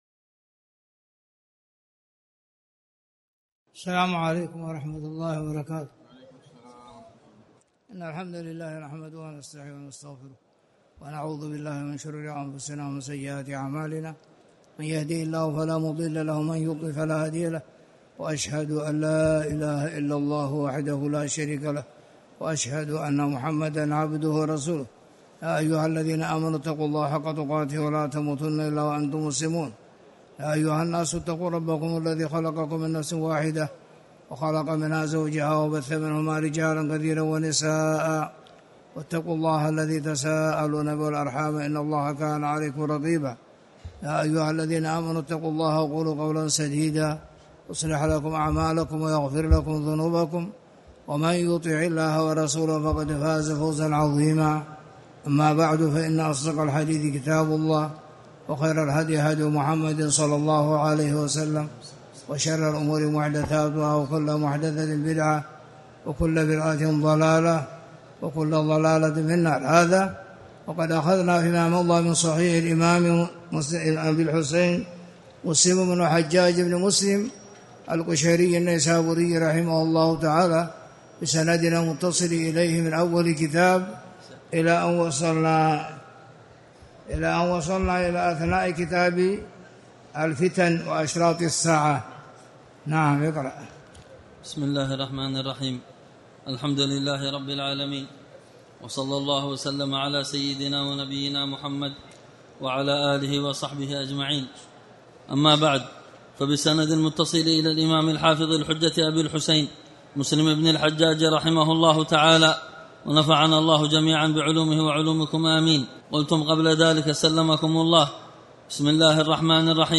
تاريخ النشر ٢٢ ذو القعدة ١٤٣٨ هـ المكان: المسجد الحرام الشيخ